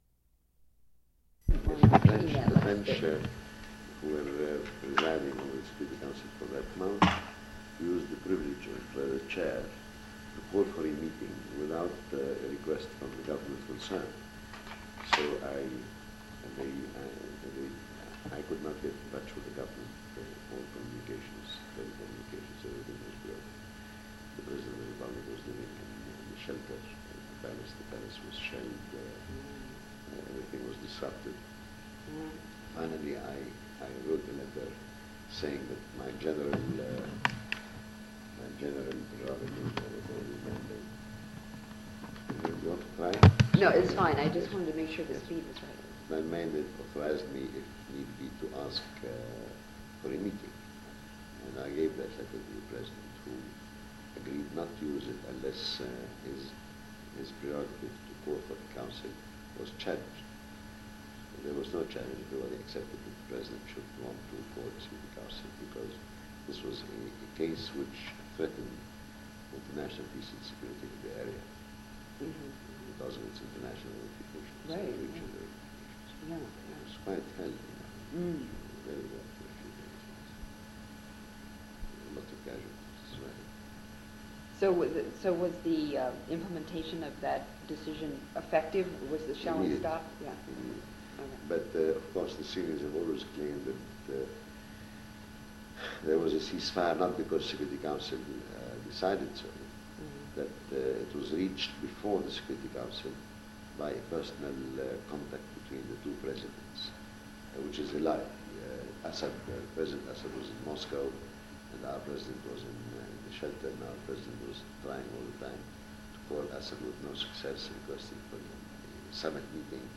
Interview with Ghassan Tueni / - United Nations Digital Library System